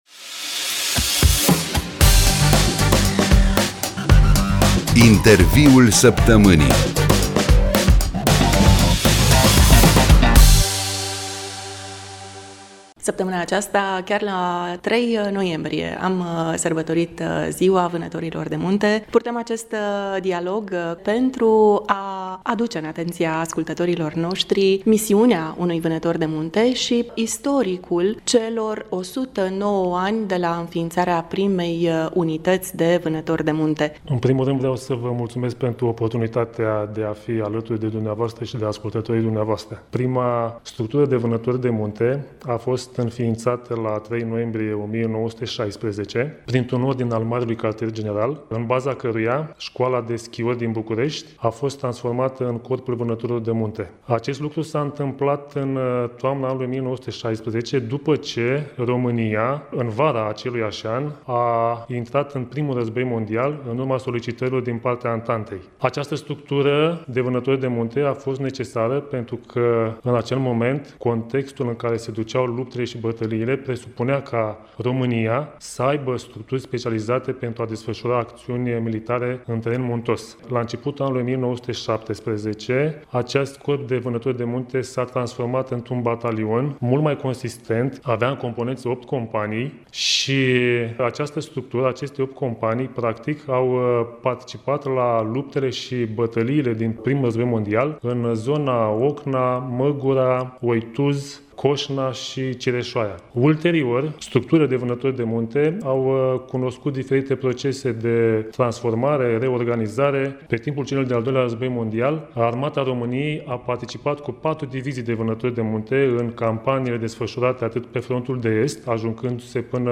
Interviul-Saptamanii-8-noi25-SCURT.mp3